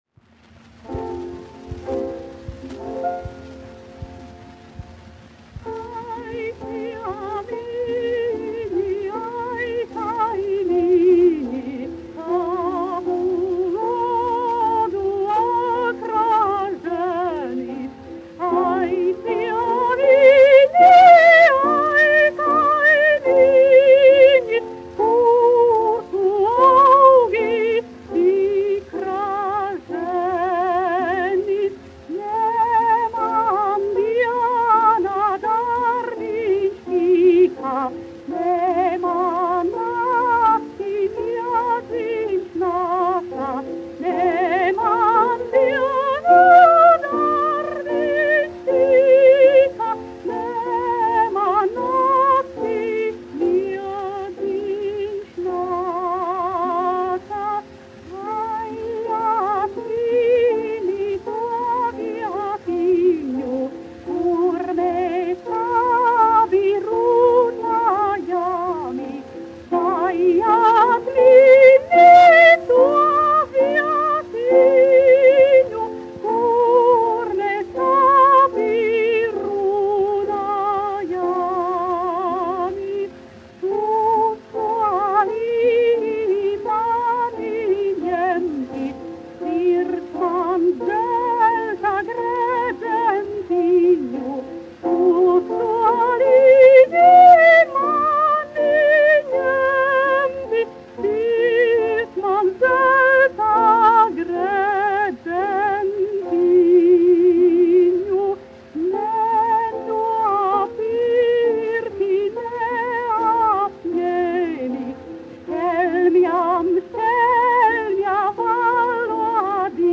Jāzeps Vītols, 1863-1948, aranžētājs
1 skpl. : analogs, 78 apgr/min, mono ; 25 cm
Latviešu tautasdziesmas
Skaņuplate